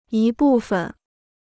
一部分 (yī bù fen): portion; part of.